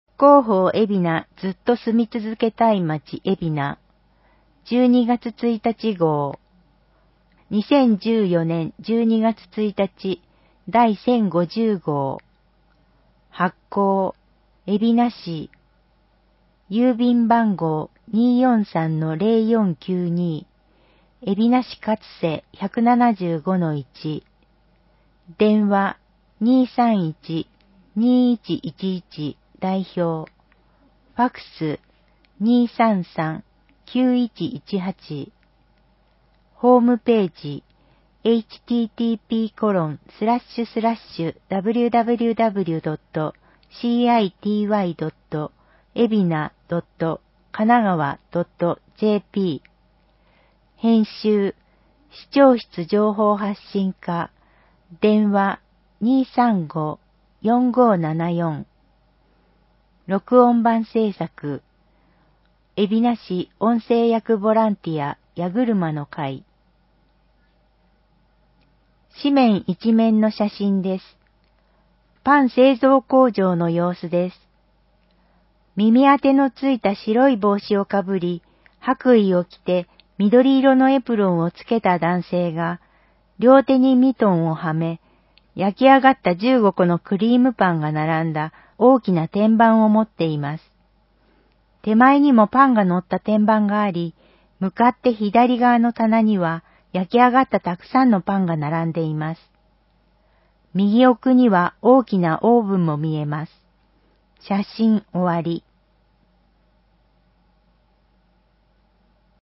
広報えびな 平成26年12月1日号（電子ブック） （外部リンク） PDF・音声版 ※音声版は、音声訳ボランティア「矢ぐるまの会」の協力により、同会が視覚障がい者の方のために作成したものを登載しています。